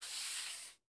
slide (2).wav